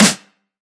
鼓和循环播放 " 高音小鼓
描述：小鼓
标签： 大声的 单一的
声道立体声